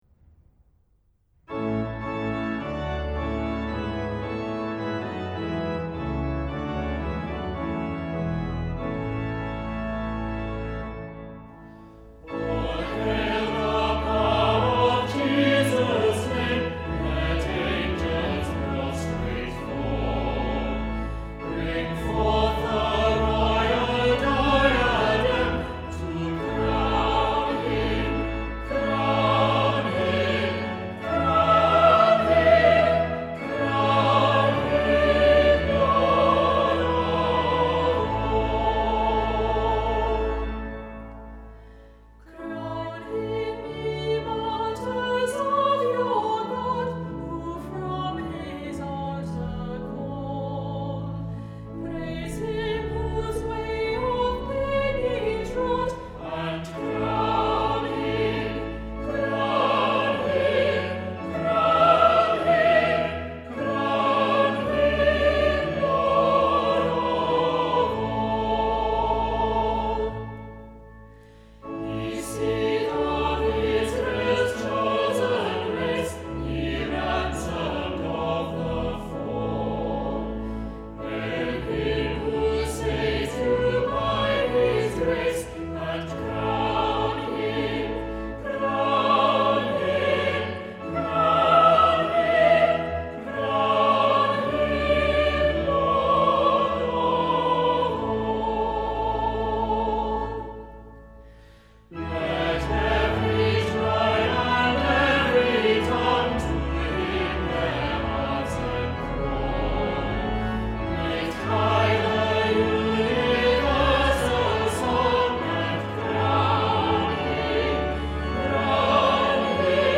Gospel Hymn; All hail the power of Jesus’ name!